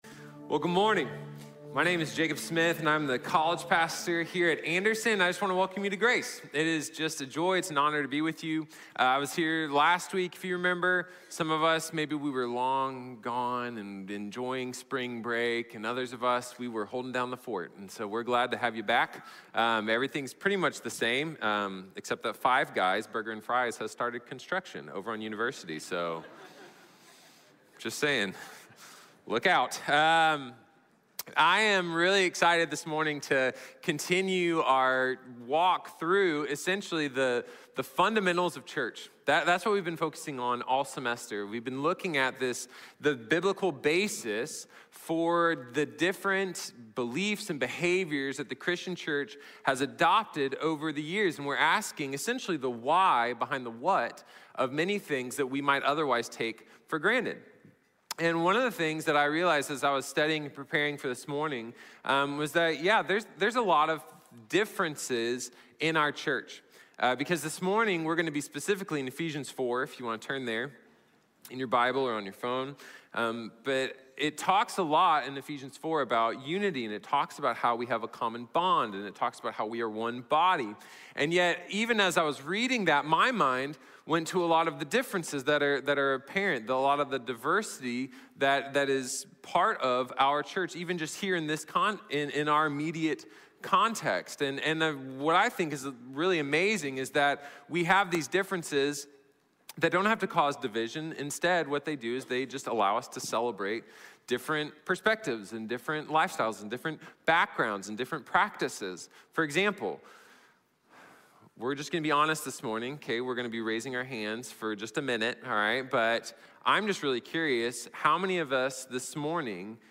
Unified Serving | Sermon | Grace Bible Church